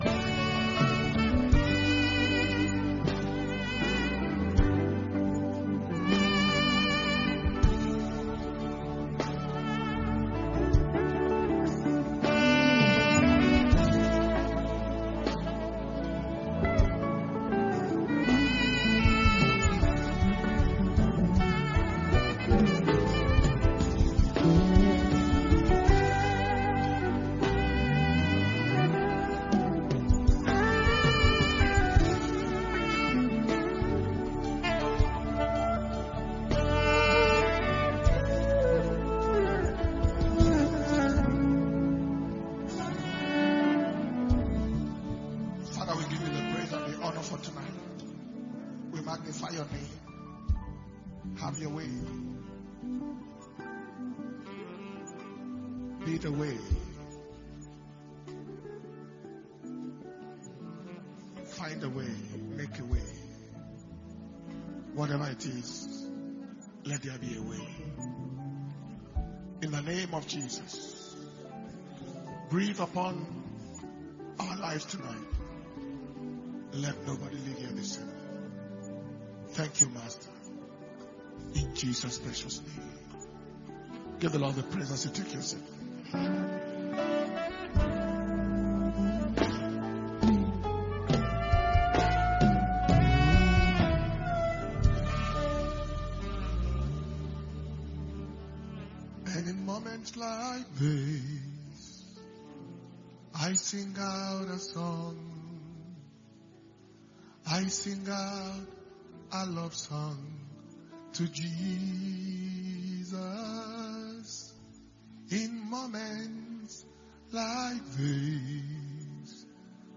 Power Communion Service – Wednesday, 8th March 2023